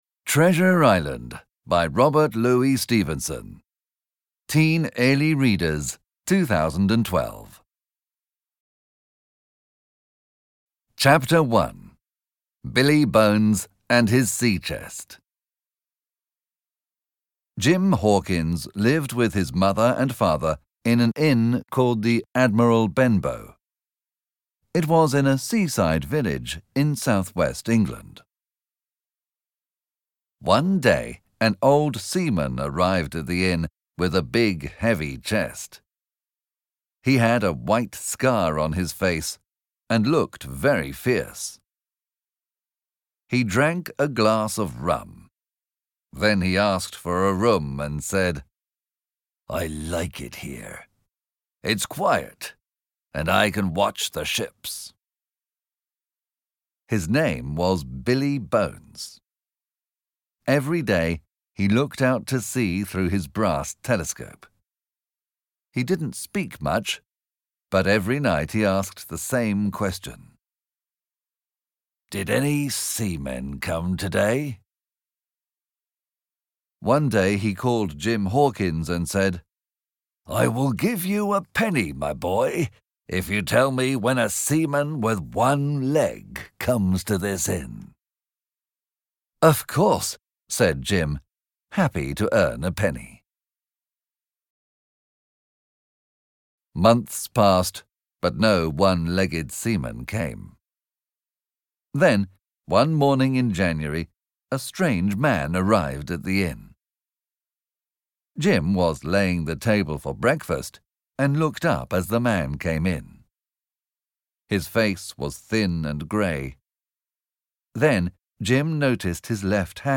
Obtížnost poslechu odpovídá jazykové úrovni A2 podle Společného evropského referenčního rámce, tj. pro studenty angličtiny na úrovni mírně pokročilých začátečníků.
AudioKniha ke stažení, 10 x mp3, délka 1 hod., velikost 82,6 MB, česky